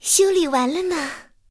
KV-2修理完成提醒语音.OGG